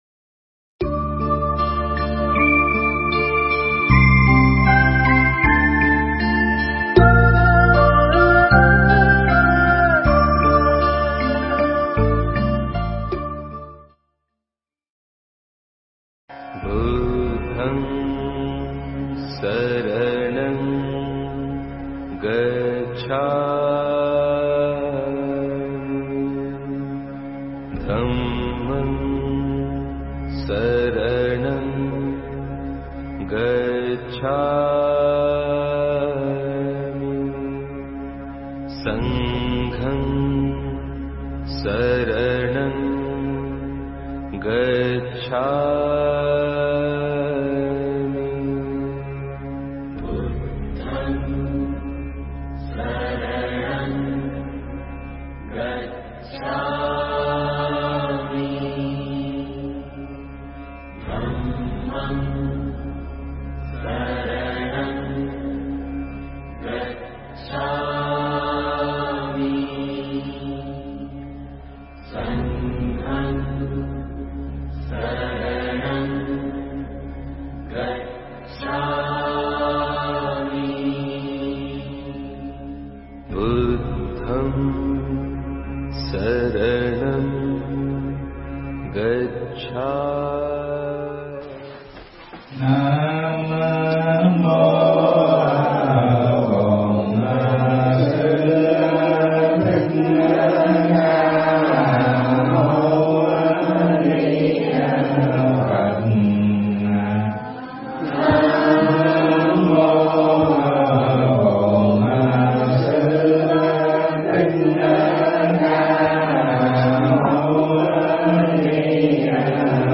Nghe Mp3 thuyết pháp Kinh Trung Bộ – Nhất Thiết Lậu Hoặc